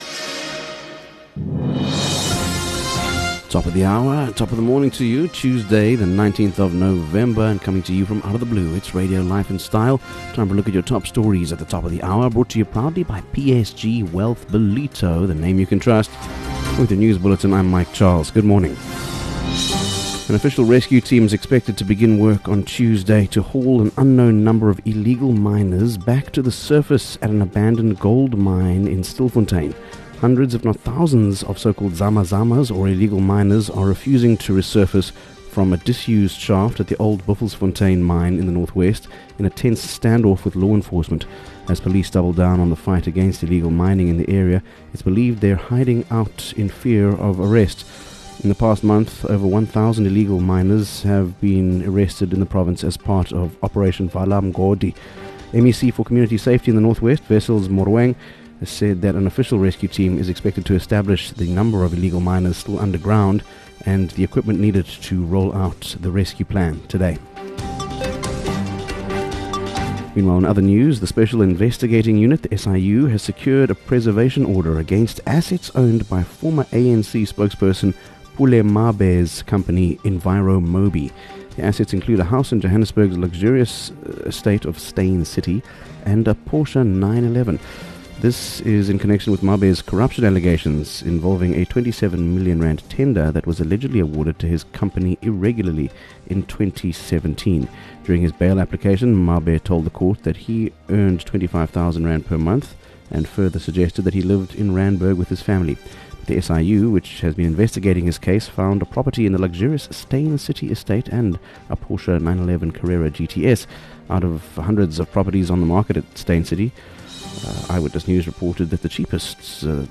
19 Nov Morning News Bulletin - Tue 19 November 2024